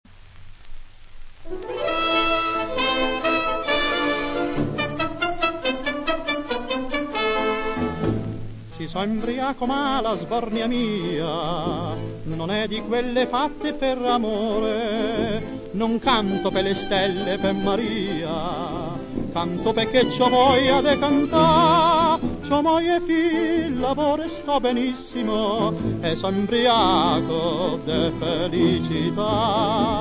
Tenore